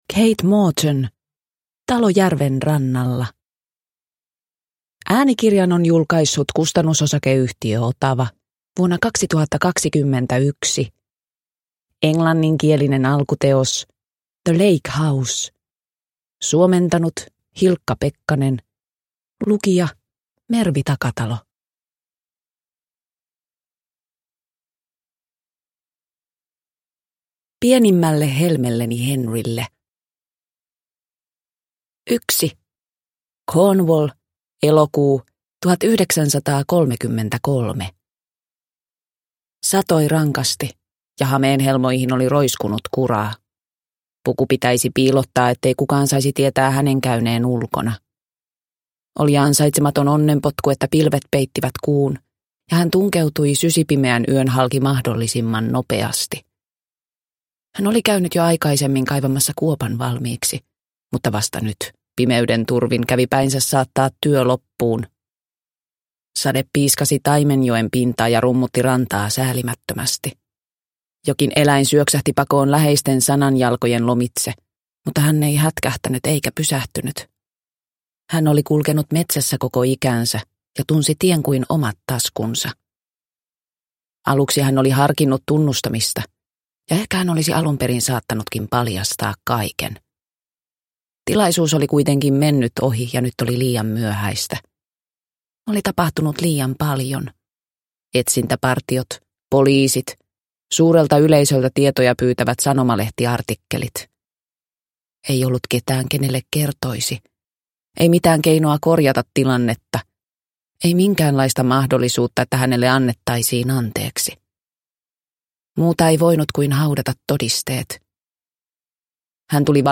Talo järven rannalla – Ljudbok – Laddas ner